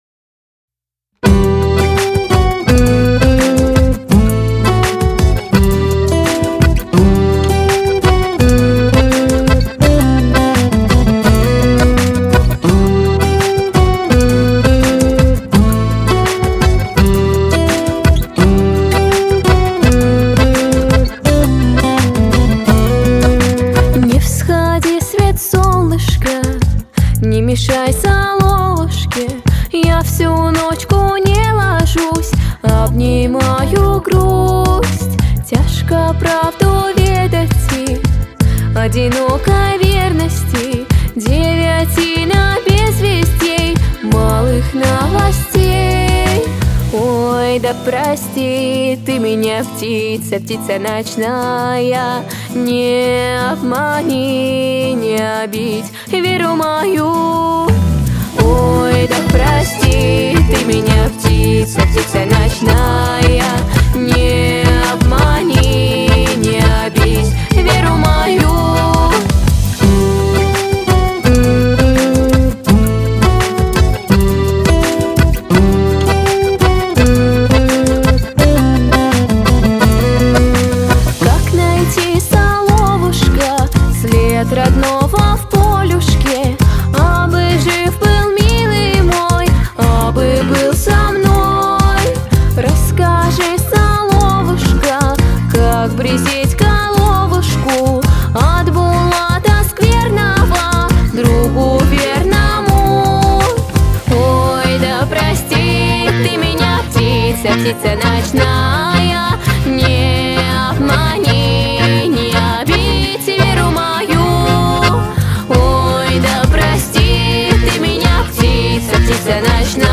Фантазии соловьиной волшебной летней ночи.